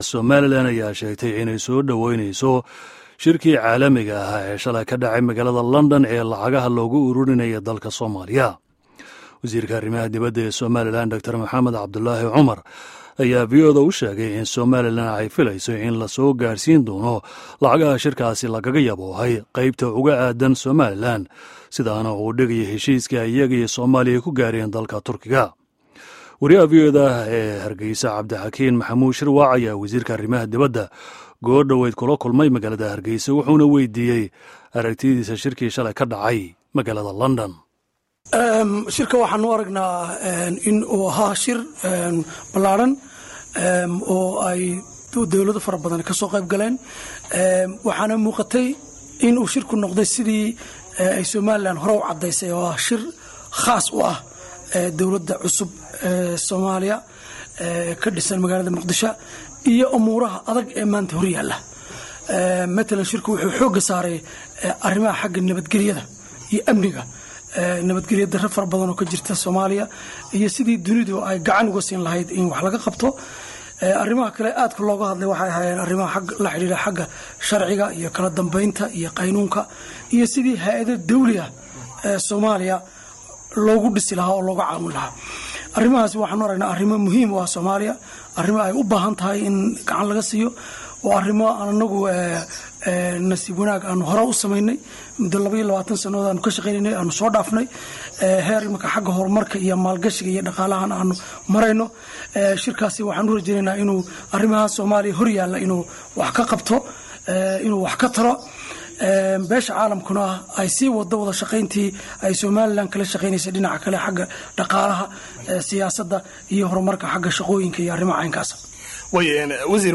Wareysiga Wasiirka Arrimaha Dibadda Somaliland